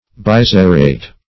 Biserrate \Bi*ser"rate\, a. [Pref. bi- + serrate.]